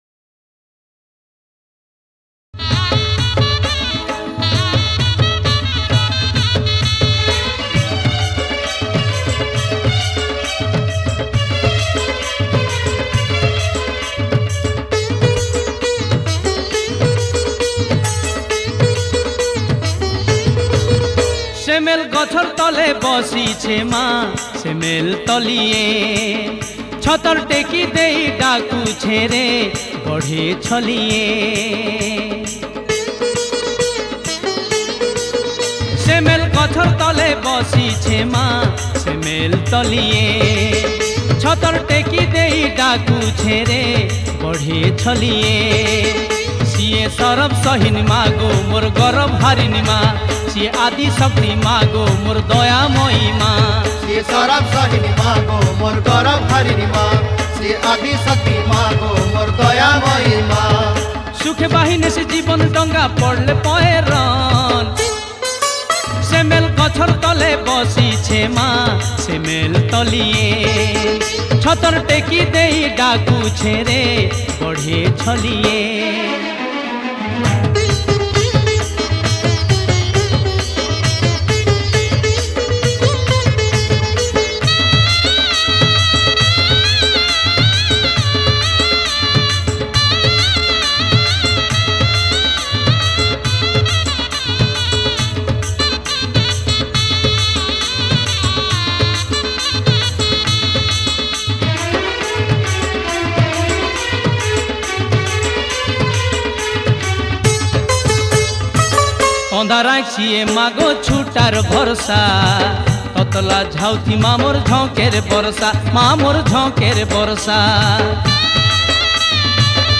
Category: Sambalpuri Bhajan Single Songs